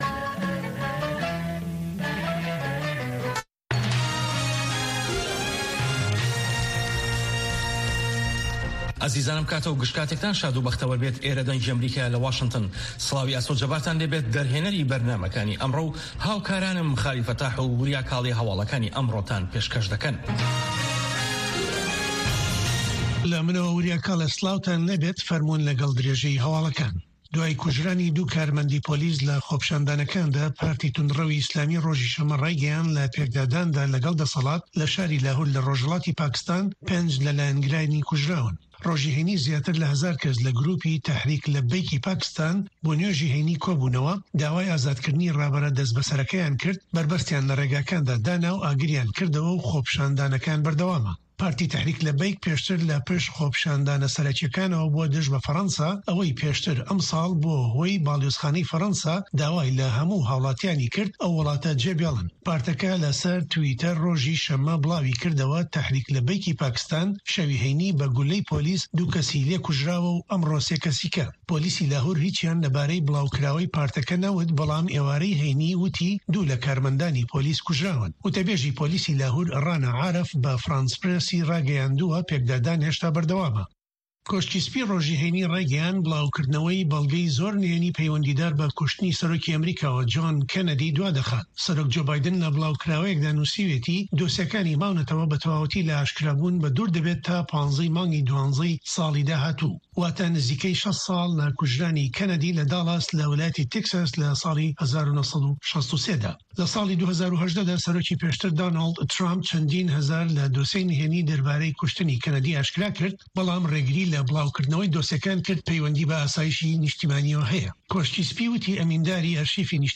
Nûçeyên 3’yê paşnîvro
Nûçeyên Cîhanê ji Dengê Amerîka